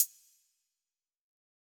SouthSide Hi-Hat (28).wav